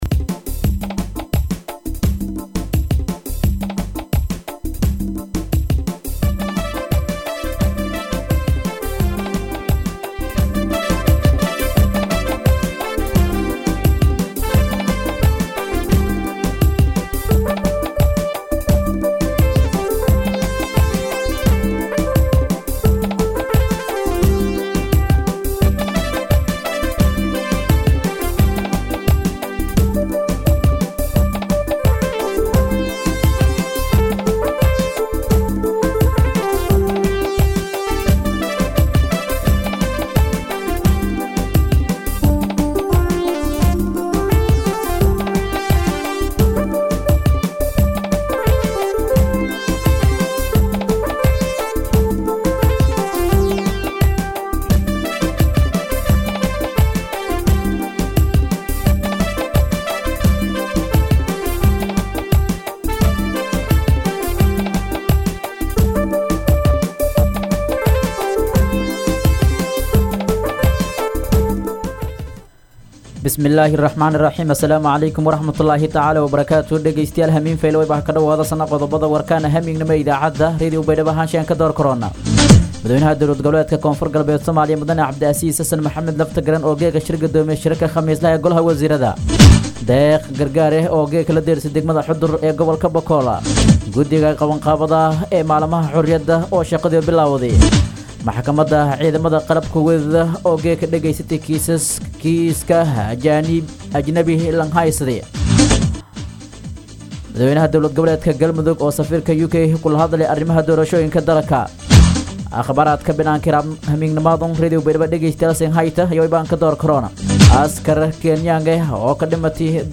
warka-habeenimo-24.mp3